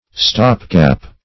Stop-gap \Stop"-gap`\, n.